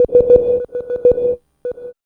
4507R SYNTON.wav